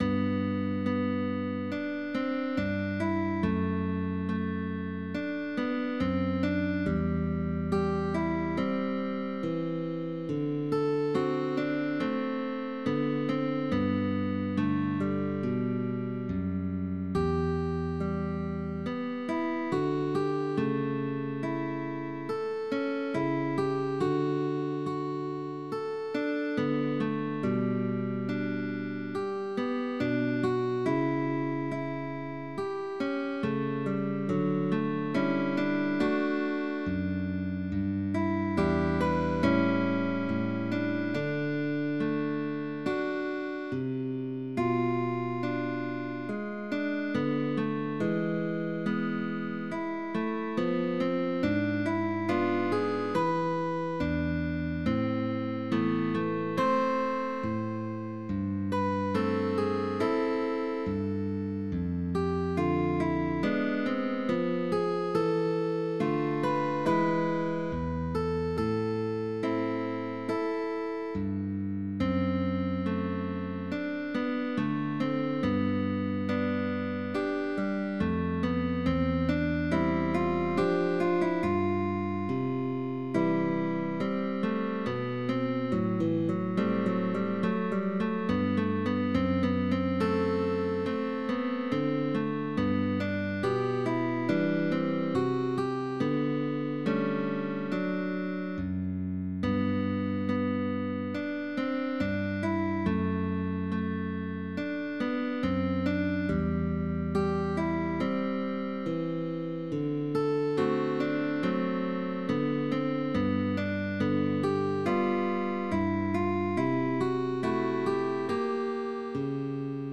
La guitarra 2 (acompañamiento) mantiene bajos y armonía.